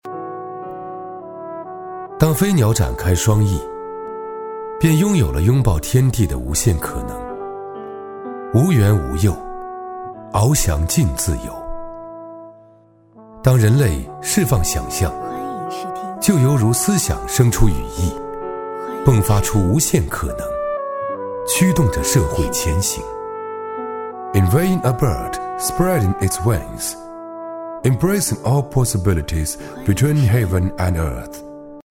男369-英文【中英诗文】
男369-中英双语 大气浑厚
男369-英文【中英诗文】.mp3